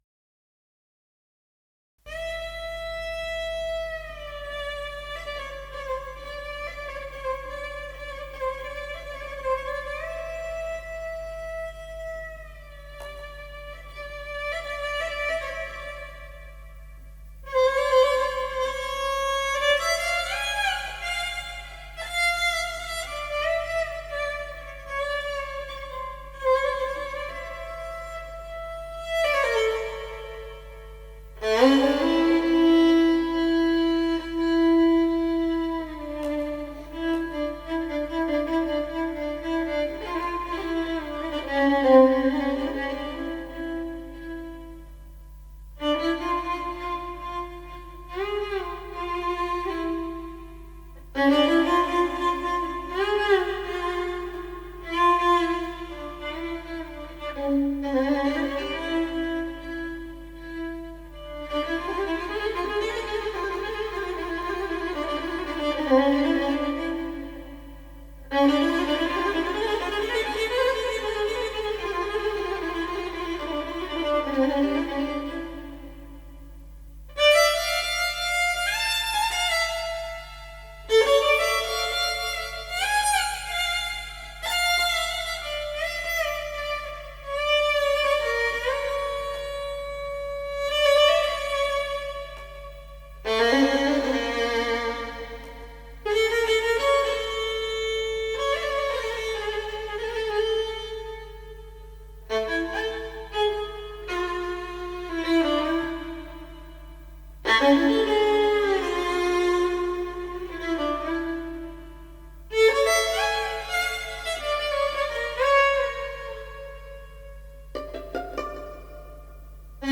موسیقی اینسترومنتال موسیقی بیکلام